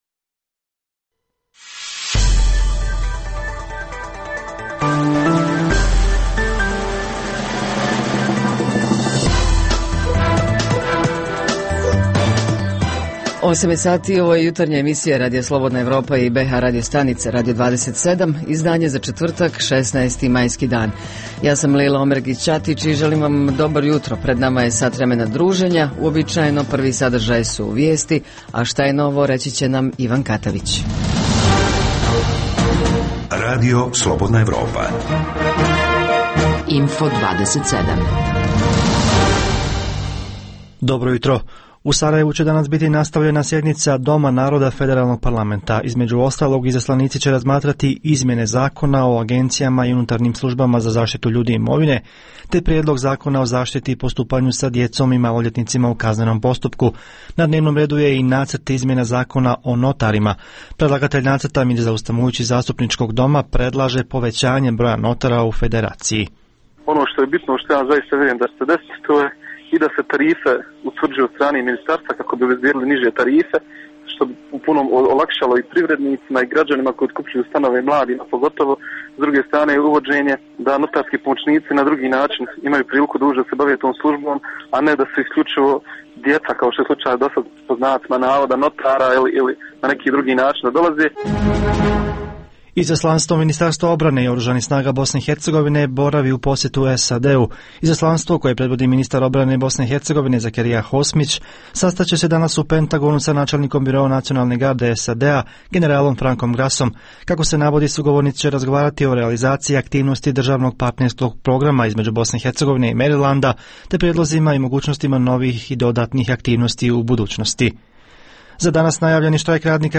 U rubrici Info plus - novosti iz Sarajeva i Brčko distrikta saznajemo iz razgovora s našim dopisnicima iz ovih gradova Iz jučerašnjeg programa Radija Slobodna Evropa priča o dokumentu Ahdnama, starom 550 godina, a kojim je legimitirana prisutnost i djelovanje bosanskih franjevaca nakon pada Bosne pod vlast Osmanlija.